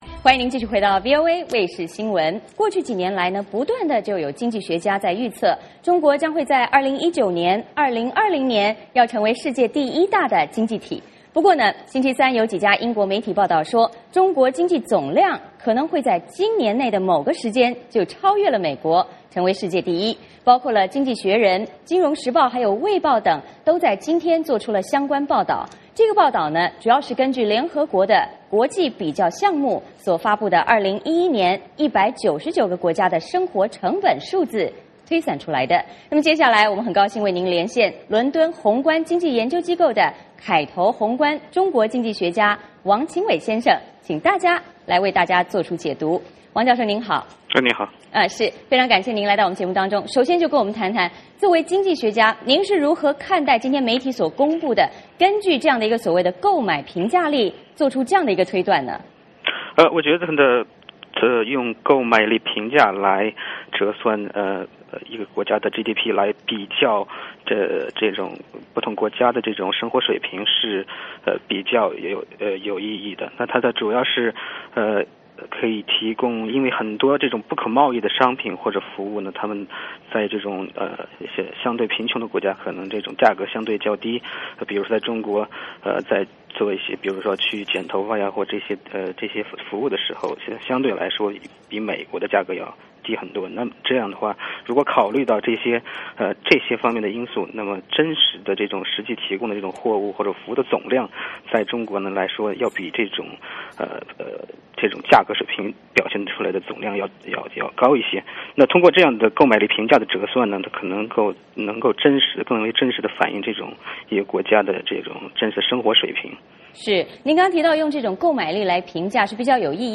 VOA连线：英媒指中国经济总量将在今年跃居世界第一